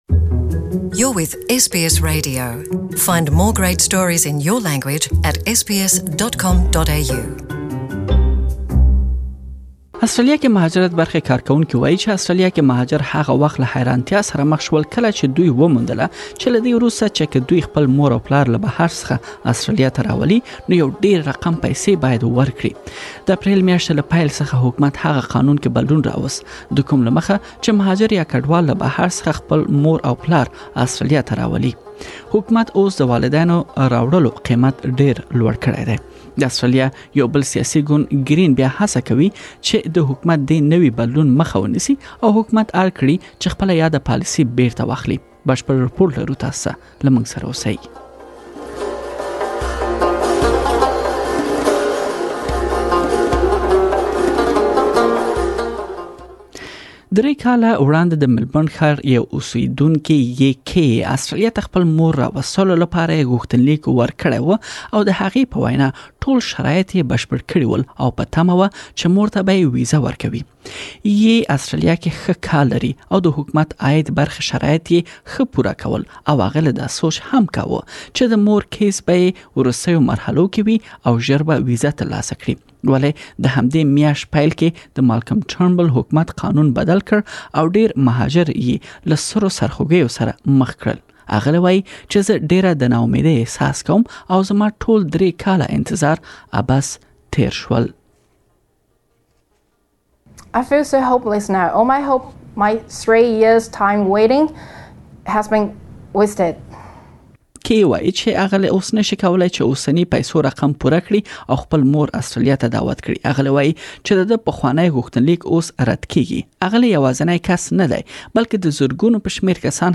Please listen to the full report in Pashto.